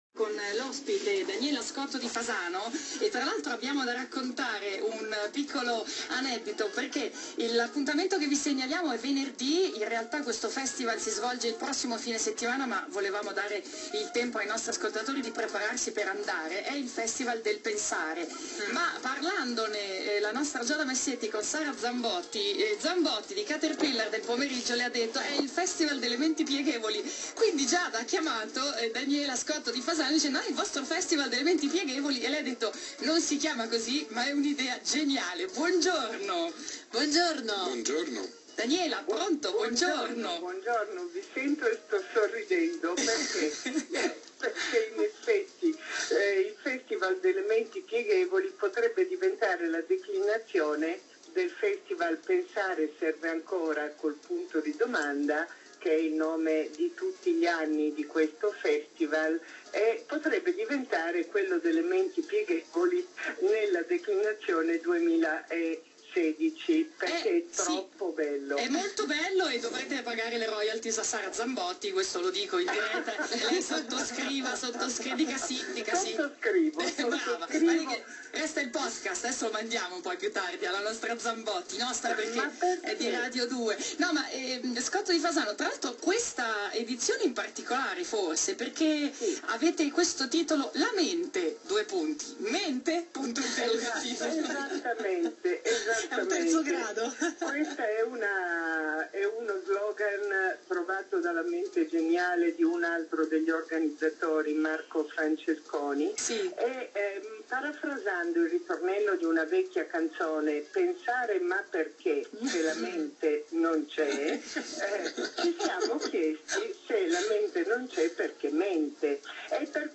rai2_intervista_festival_pensare.m4a